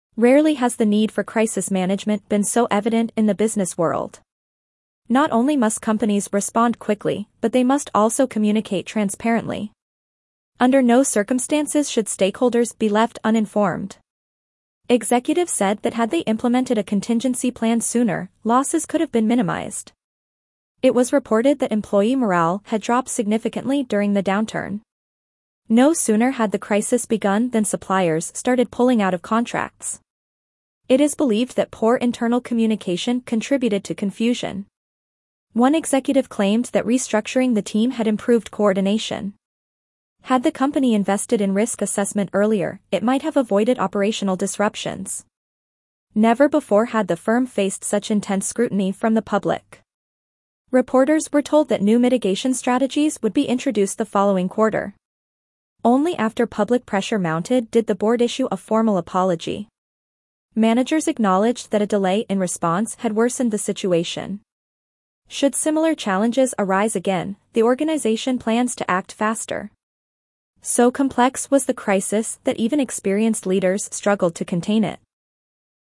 Instructions: Listen carefully and write down what you hear. Your teacher will read the passage aloud.